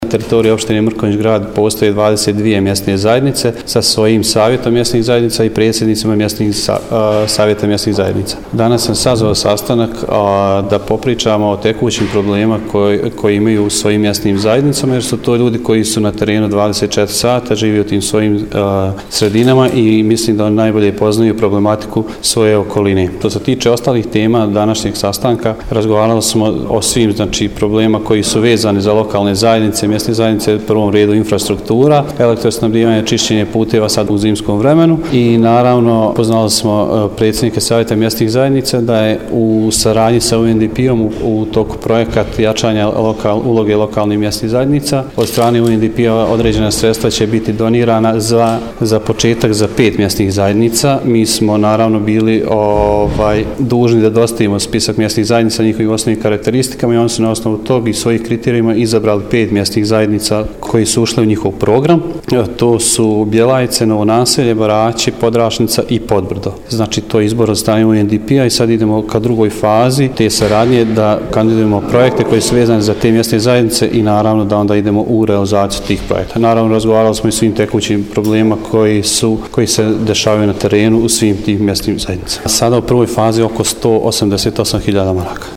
Na održanom sastanku sa predstavnicima 22 mjesne zajednice razgovaralo se o tekućim problemima u svakoj od mjesnih zajednica. U nastavku poslušajte izjavu načelnika Vođevića –
izjava